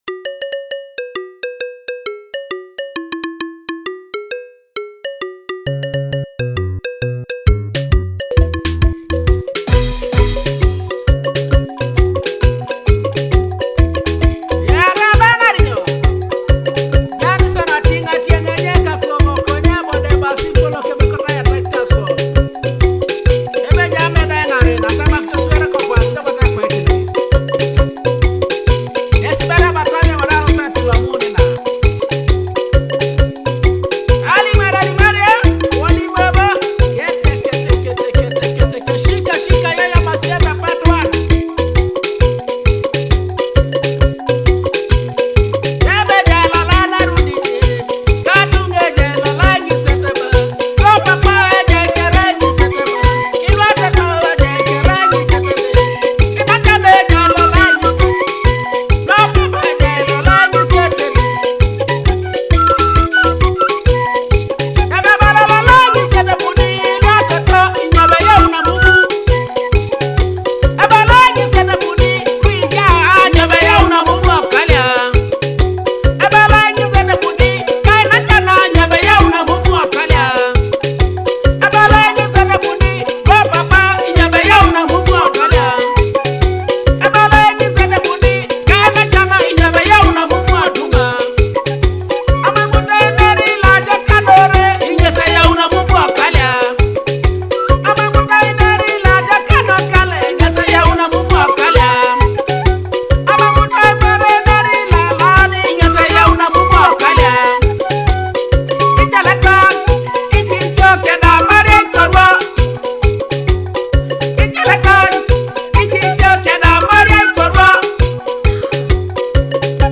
traditional songs